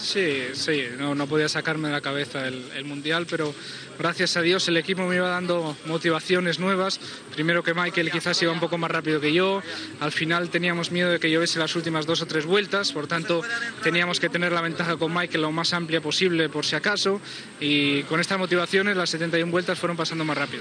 Paraules de Fernando Alonso després de la cursa i de guanyar el seu primer campionat del món de pilots de Fórmula 1.